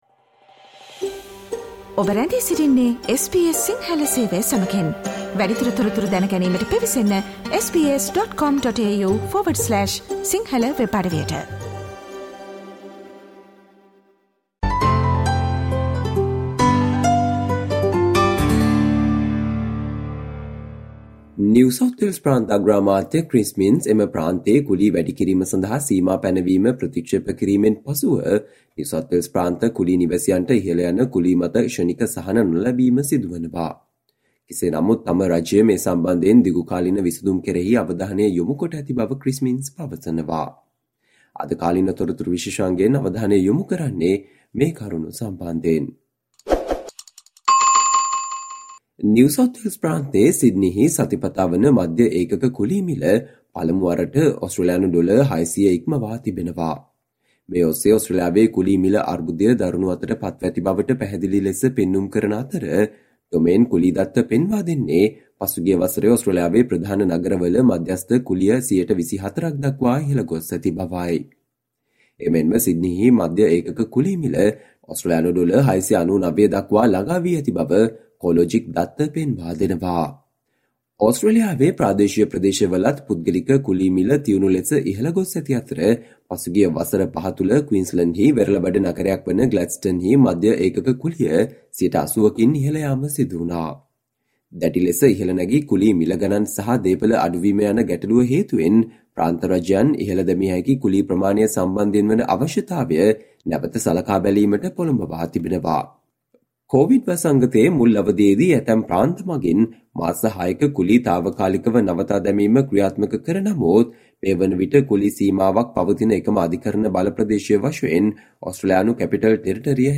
Today - 10 April, SBS Sinhala Radio current Affair Feature on Albanese backs pay increase for low paid workers